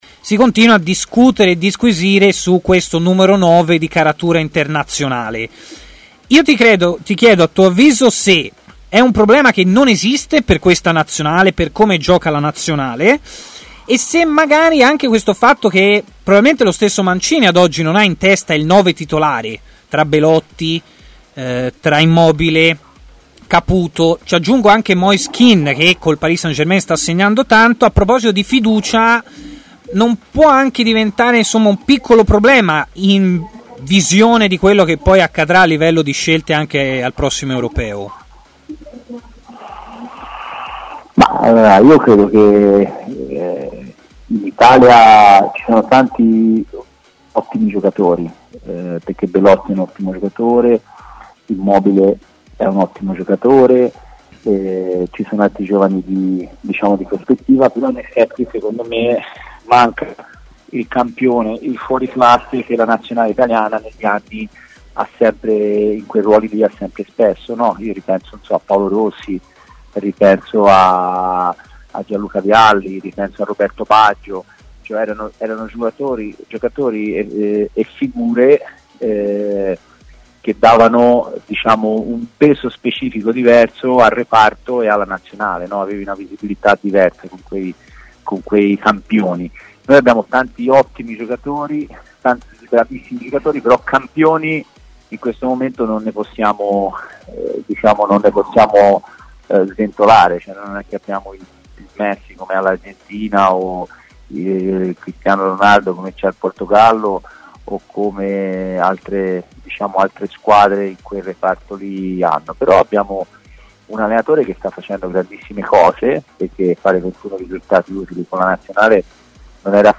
Intervenuto ai microfoni di TMW Radio, Cristiano Lucarelli si è espresso anche su Ciro Immobile e sull'attacco della Nazionale.